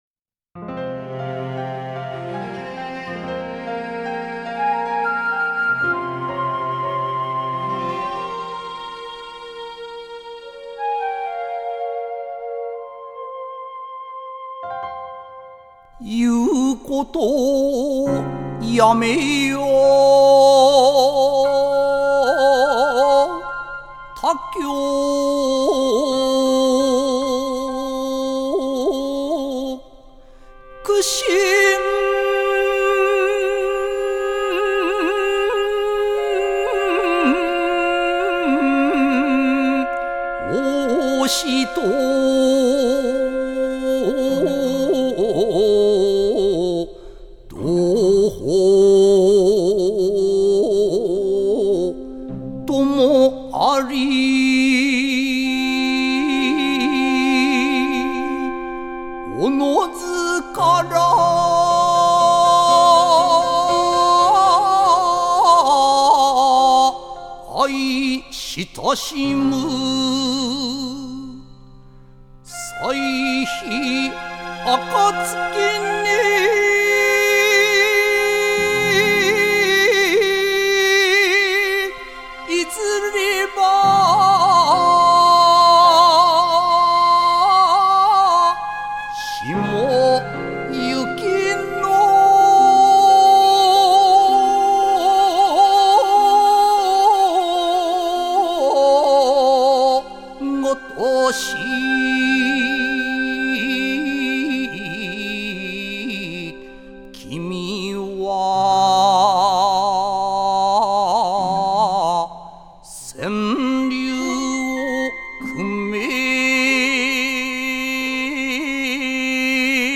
仄（そく）起こり七言絶句（しちごんぜっく）の形であって、上平声（じょうひょうしょう）十一眞（しん）韻の辛（しん）、親（しん）、薪（しん）の字が使われている。